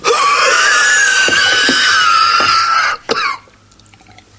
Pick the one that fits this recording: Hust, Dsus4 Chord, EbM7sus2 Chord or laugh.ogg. Hust